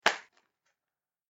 دانلود صدای قایق 3 از ساعد نیوز با لینک مستقیم و کیفیت بالا
جلوه های صوتی